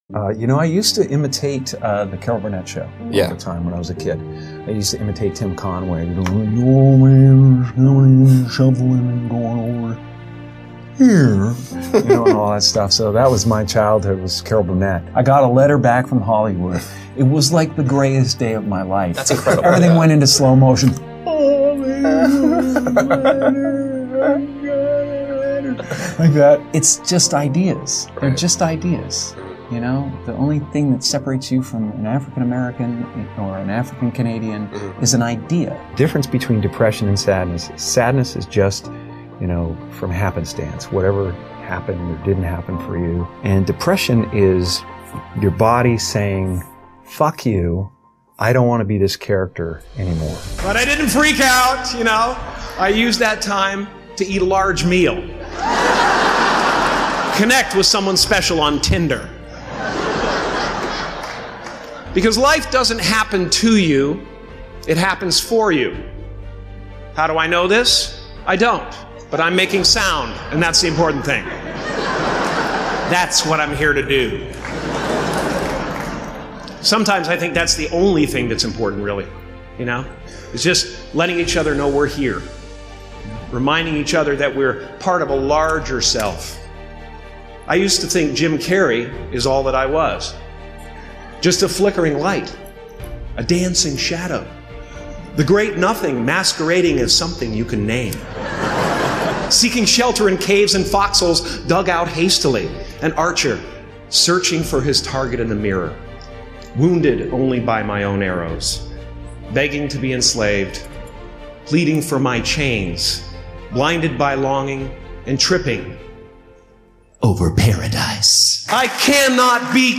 All ads in Quote of Motivation begin right at the start of each episode so nothing interrupts the moment you settle in, breathe, and feel that familiar spark rising.